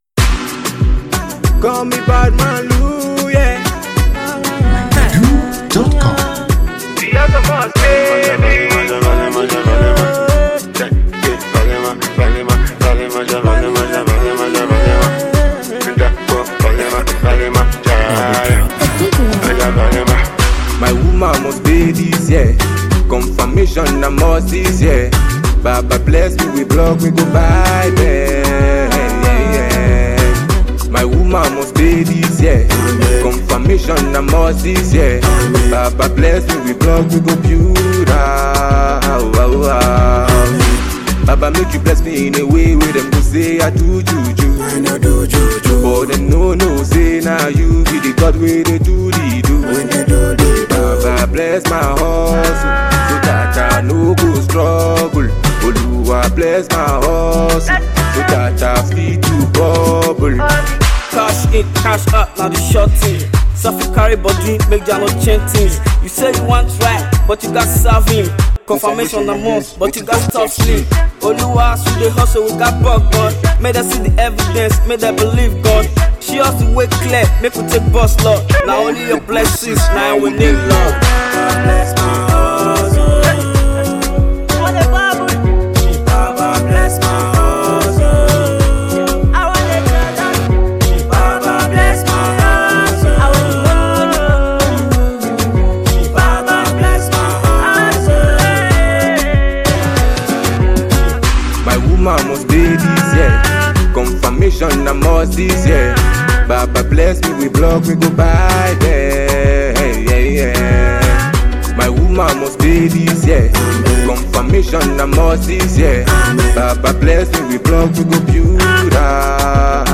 banging single
The new record features fast rising pop star
that track that will definitely lift up your spirit